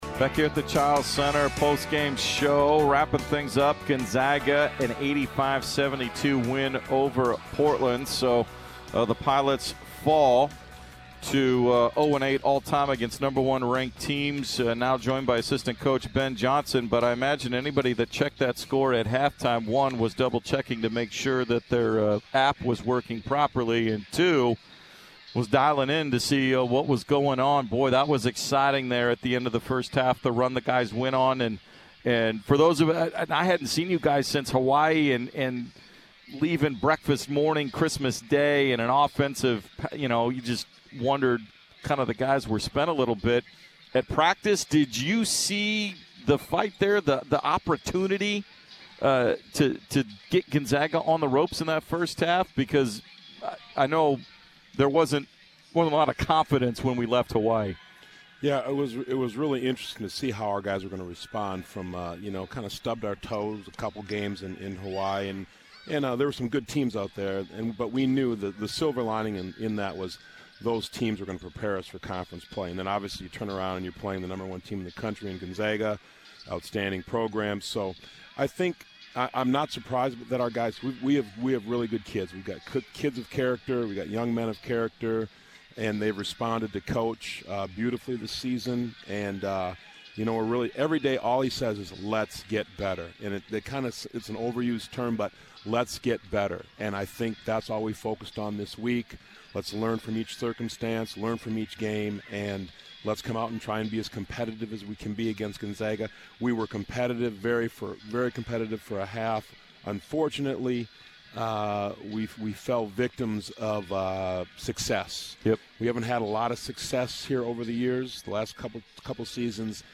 Men's Hoops Post-Game Interview vs. #1 Gonzaga